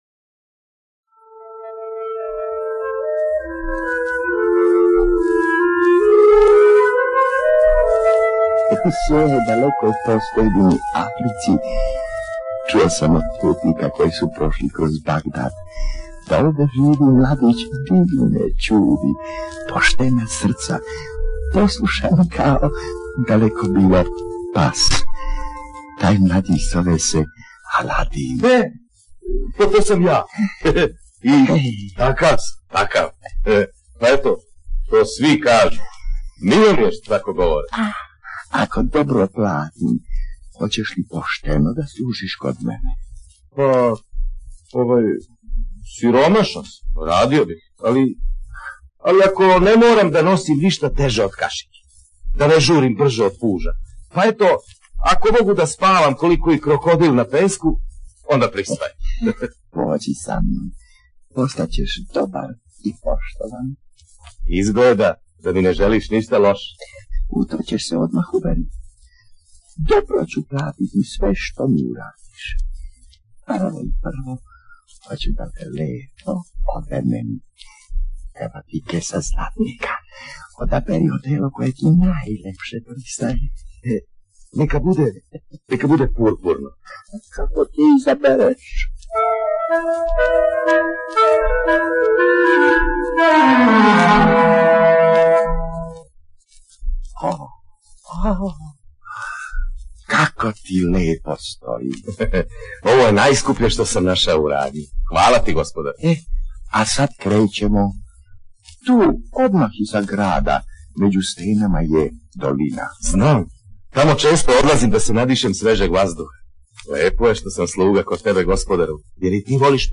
Ovo su stari, već zaboravljeni snimci sa gramofonskih ploča „singlica“, koje je izdao PGP-RTB šezdesetih godina prošlog veka u ediciji „Dečji gramofon“. Poznate bajke pričaju naši proslavljeni glumci.